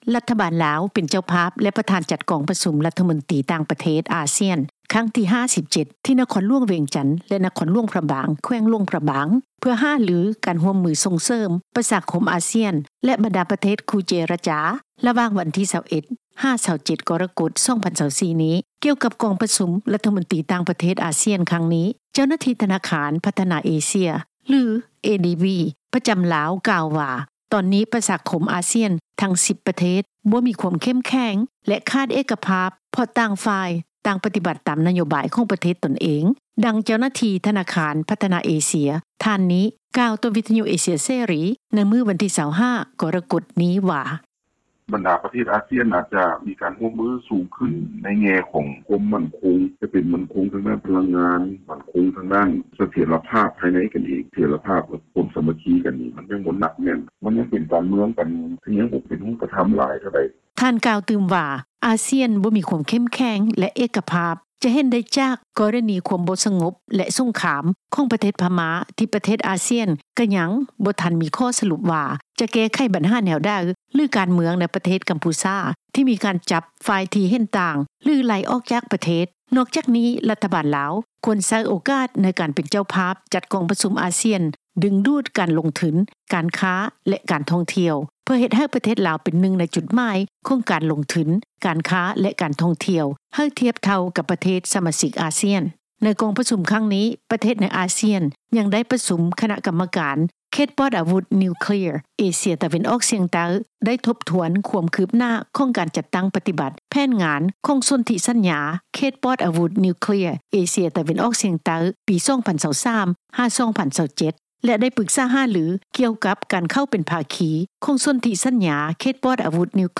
ດັ່ງ ຊາວລາວ ທ່ານນີ້ ກ່າວຕໍ່ ວິທຍຸ ເອເຊັຽເສຣີ ໃນມື້ດຽວກັນນີ້ວ່າ: